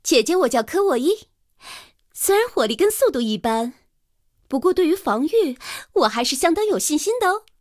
KV-1登场语音.OGG